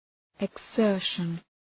Shkrimi fonetik {ıg’zɜ:rʃən}